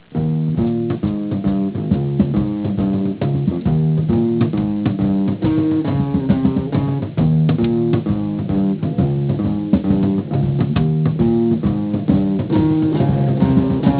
My brother in law plays bass.